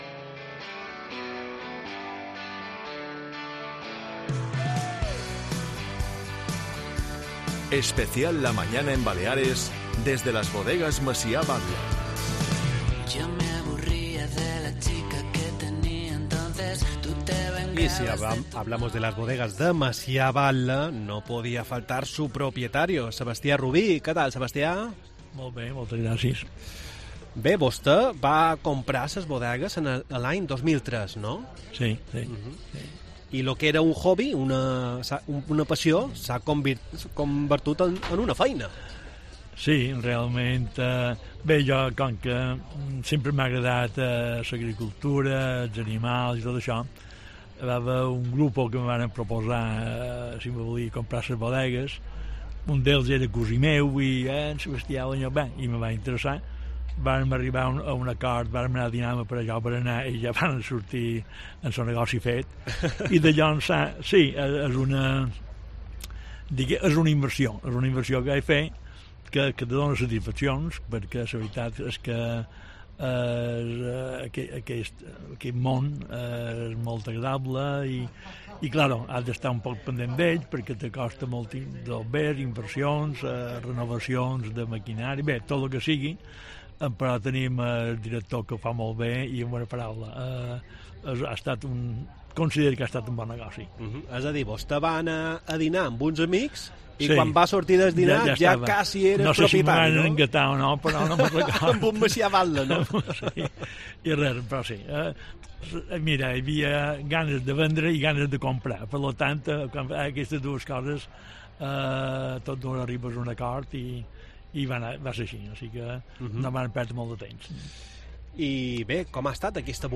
A lo largo de la entrevista nos explica de dónde viene el eslogan "Vi i Art", así como de las diferentes campañas solidarias en las que colabora la bodega.